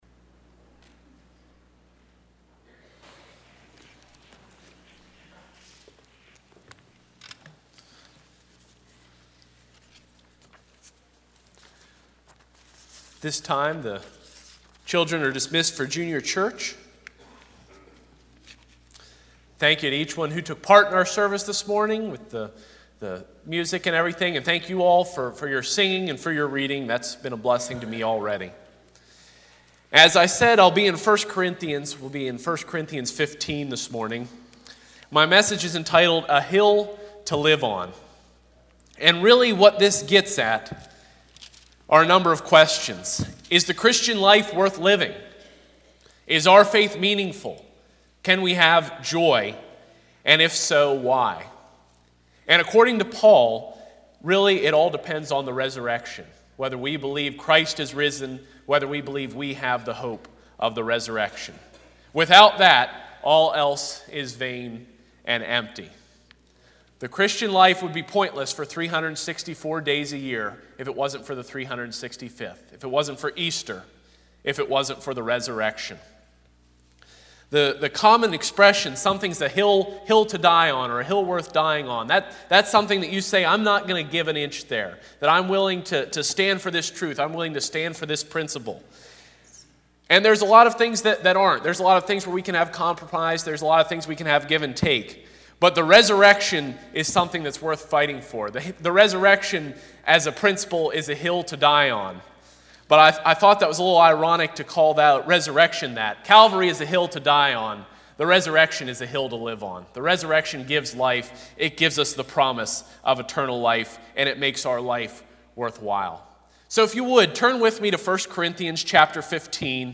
I Corinthians 15:11-21 Service Type: Sunday 9:30AM “A Hill to Live On” I. The Deception II.